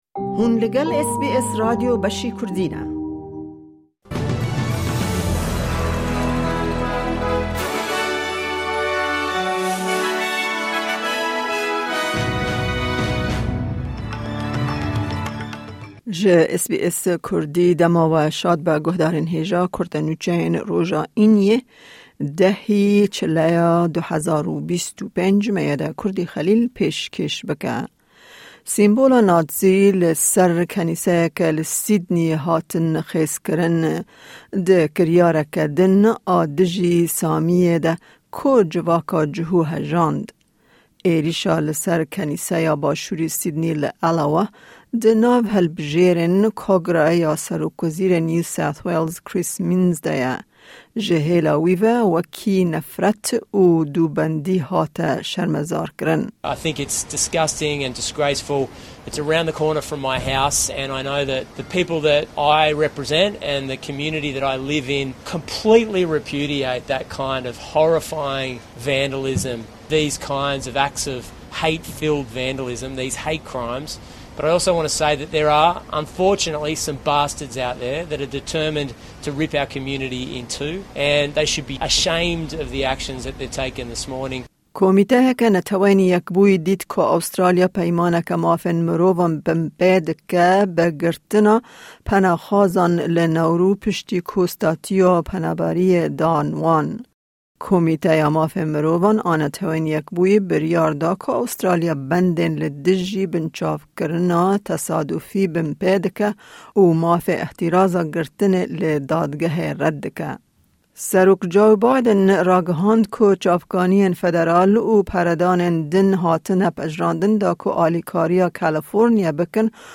Kurte Nûçeyên roja Înî 10î Çileya 2025